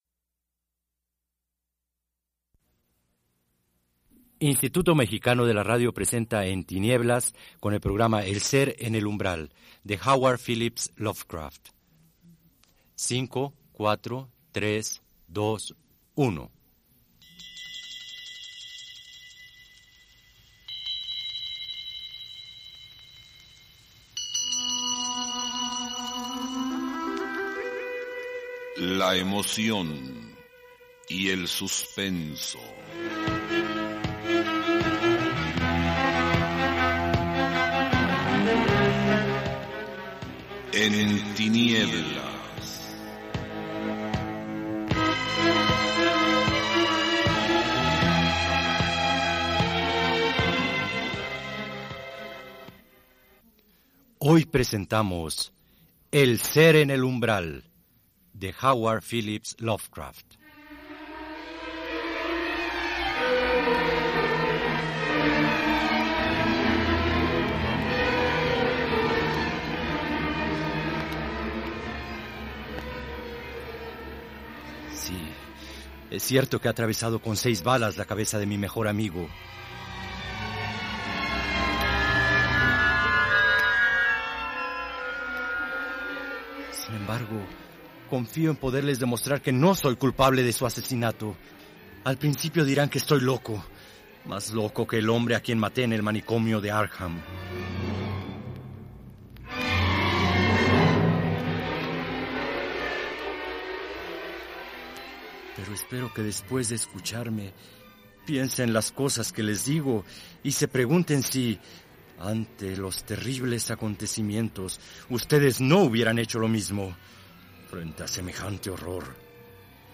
La serie En tinieblas, presenta los mejores relatos de suspenso, de autores consagrados en el género como Edgar Allan Poe, Howard Phillips Lovecraft, Alejandro Dumas, así como Elena Garro y Carlos Fuentes, mediante adaptaciones radiofónicas.